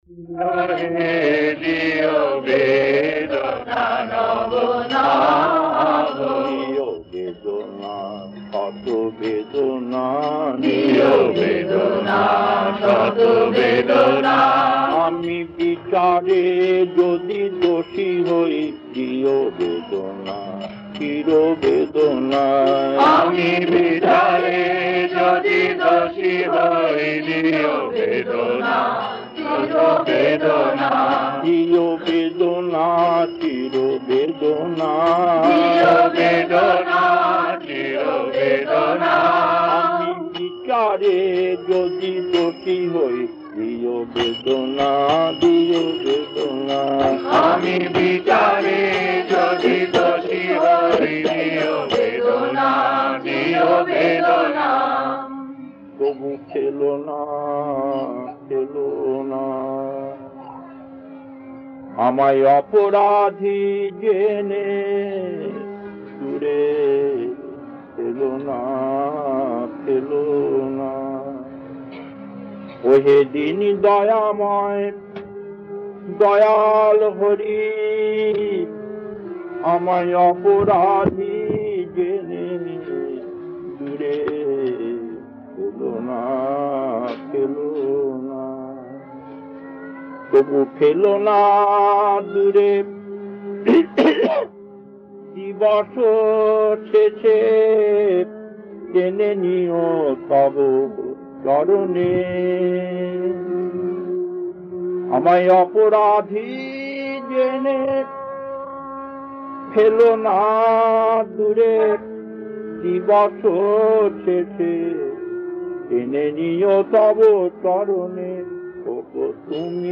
Kirtan E1-2A 1.